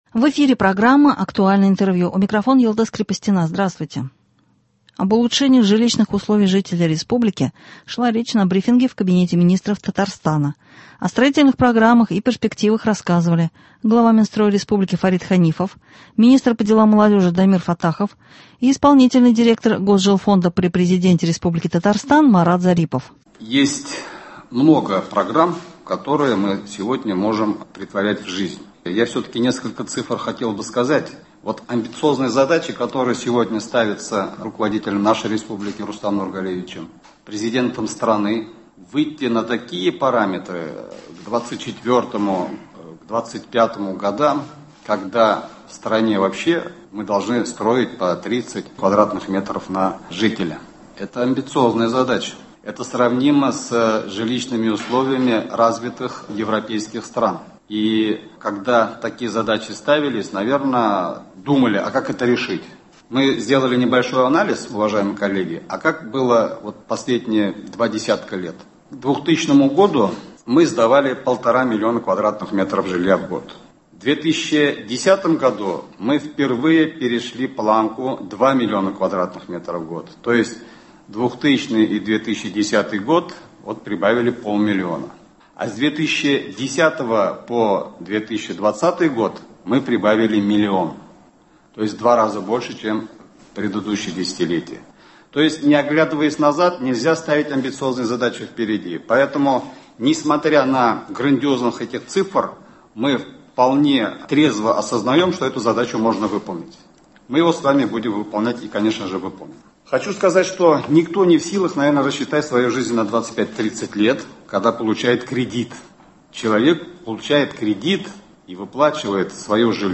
Об улучшении жилищных условий жителей Республики шла речь на брифинге в Кабинете министров Татарстана.
«Актуальное интервью». 22 июля.